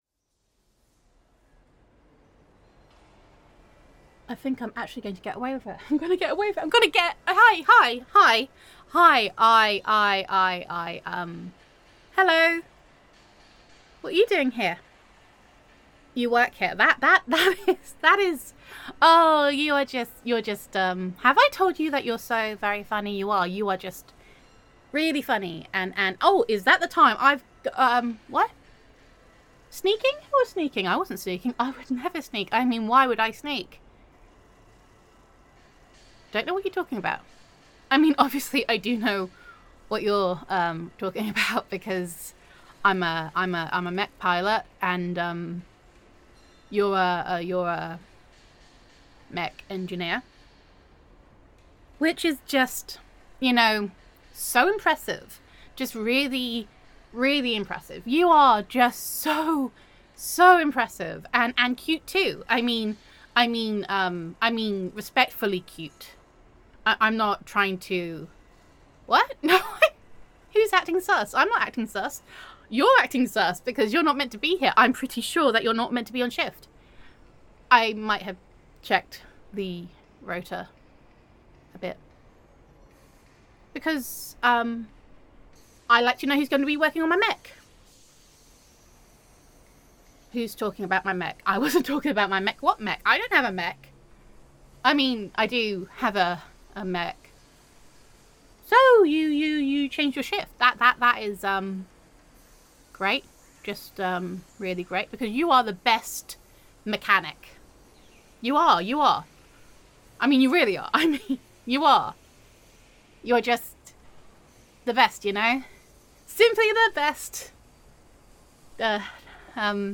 [F4A] Home in One Piece [Slow on the Uptake][Oblivious][Super Dodge][Definitely Not Avoiding You][Adorkable Mech Pilot][Friends to Lovers][Mech Mechanic Listener][Flustered Sweetheart][Gender Neutral][After Trashing Yet Another Mech an Adorkable Mech Pilot Attempts to Avoid Their Assigned Mechanic]